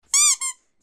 На этой странице собраны звуки магазина игрушек: весёлая суета, голоса детей, звон кассы, фоновые мелодии.
Писк утки